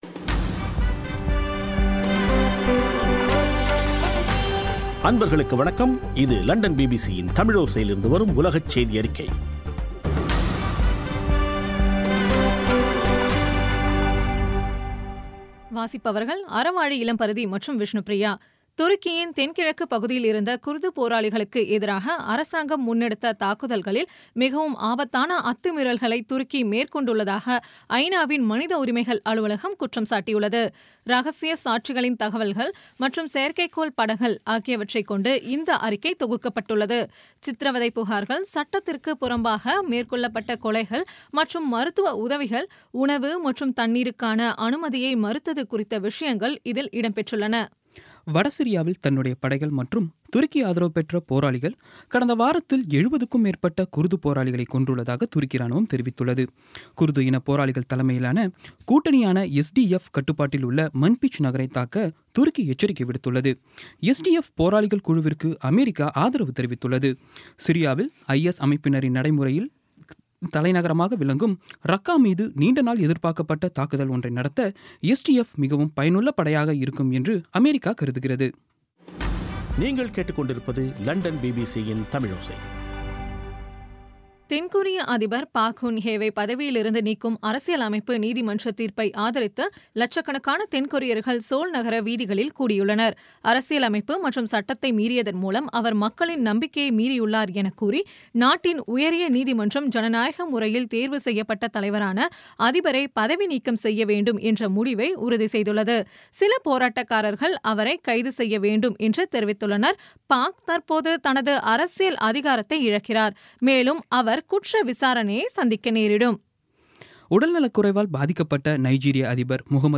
பிபிசி தமிழோசை செய்தியறிக்கை (10/03/17)